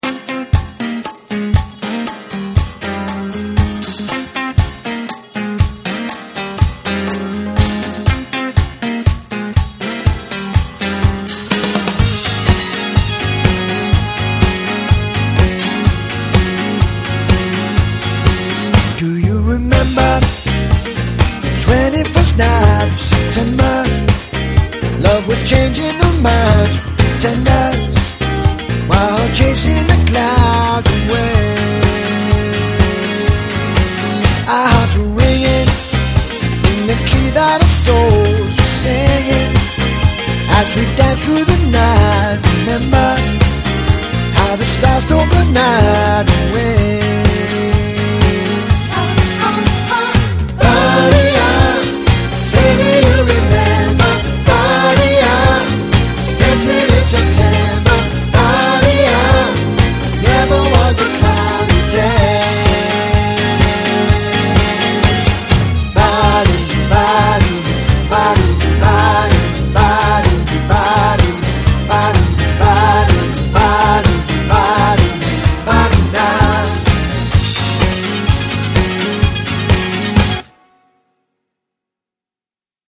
Professional Party Band